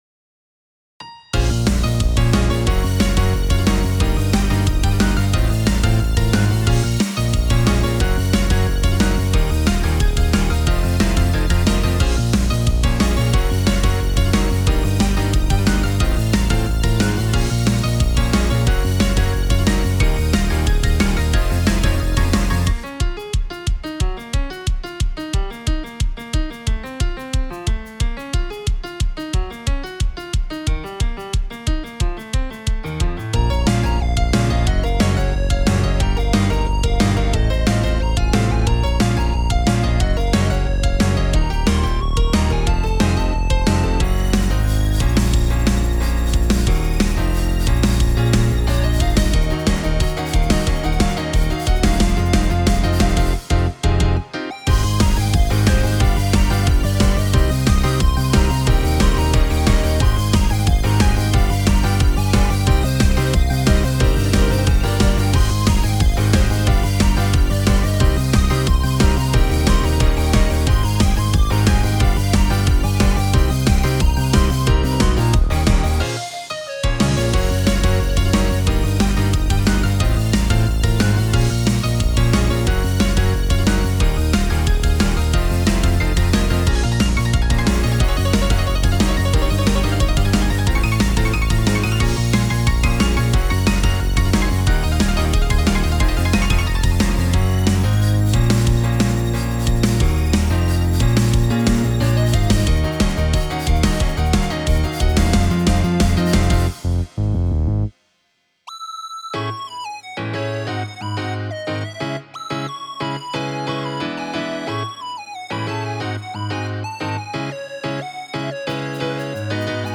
歌詞は恥ずかしいしアカウントバレちゃうからオフボーカル載せるね🫣💦💦
もしかしたら音量が小さく聞こえるかも🫣
スマホスピーカーでも聴きやすくて丁寧にミックスしてる感がある🥺
軽快でノリノリで好き🥰